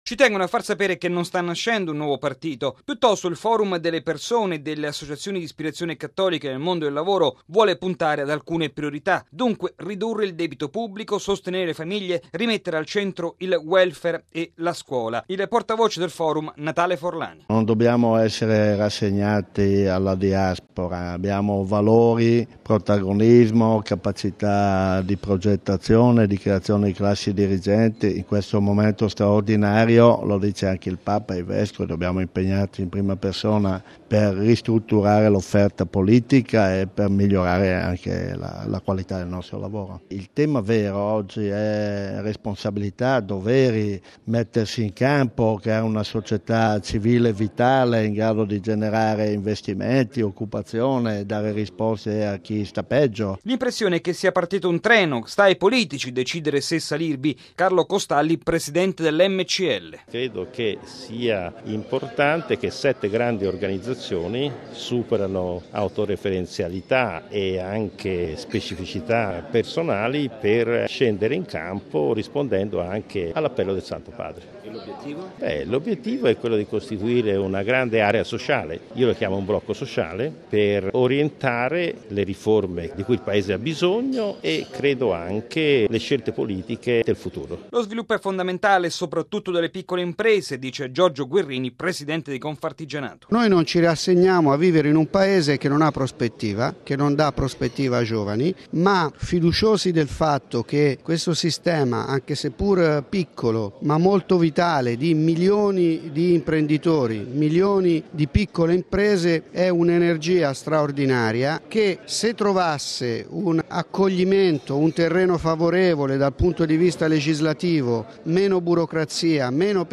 Ieri a Roma è stato presentato il Manifesto di queste organizzazioni (Cisl, Acli, Movimento cristiano lavoratori, Compagnia delle Opere, Coldiretti, Confartigianato e Confcooperative) con lo scopo di rilanciare l’impegno dei credenti per lo sviluppo del Paese. Il servizio